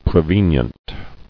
[pre·ven·ient]